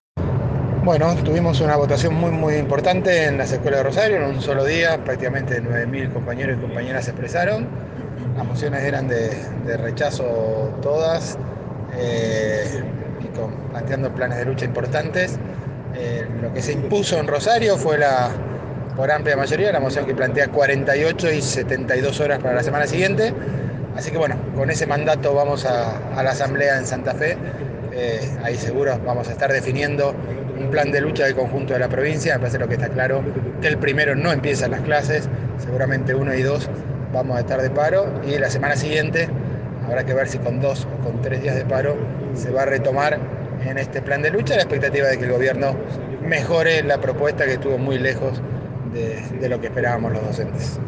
en contacto con el móvil de Cadena 3 Rosario, en Siempre Juntos.